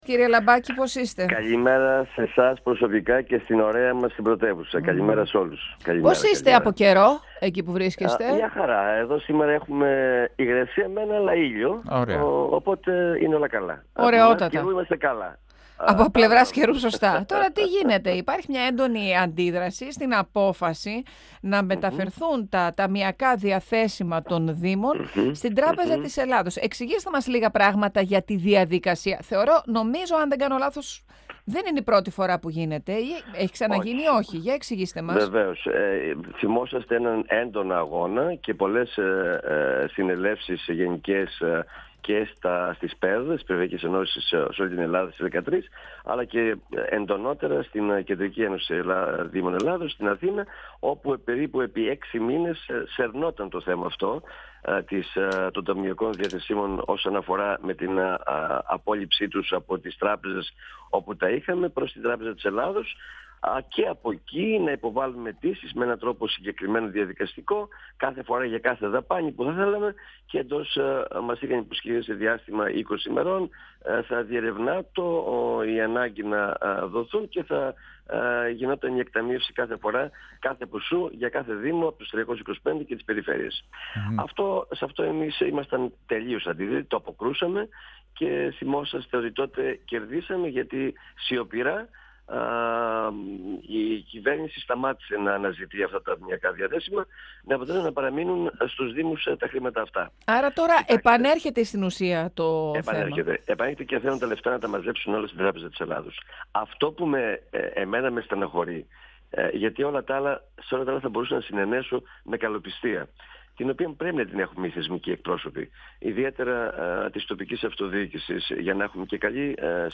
Ο δήμαρχος Αλεξανδρούπολης Ευάγγελος Λαμπάκης, στον 102 fm της ΕΡΤ3